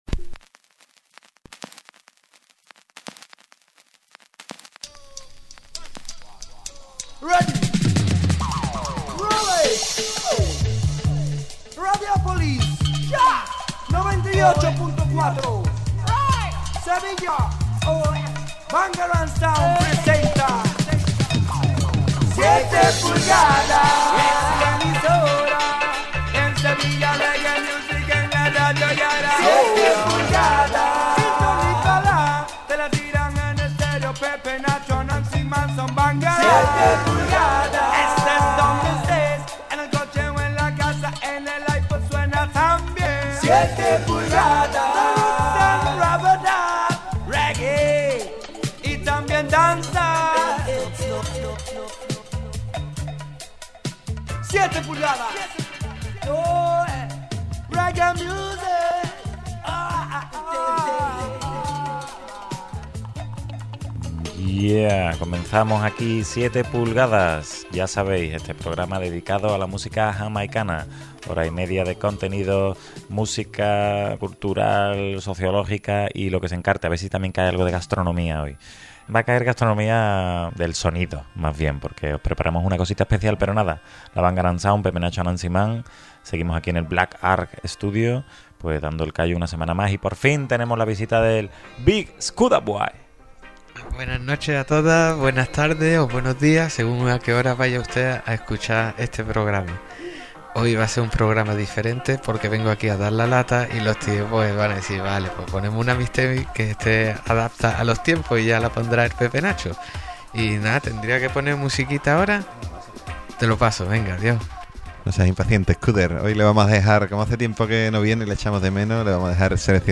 Presentado y dirigido por la Bangarang Sound y grabado en Black Arghh Studio.
Esta semana queremos volver a homenajear y a reivindicar como propia la lucha feminista, celebrando el Día Internacional de la Mujer Trabajadora con una maravillosa mixtape seleccionada y mezclada por mujeres y cargada de voces femeninas.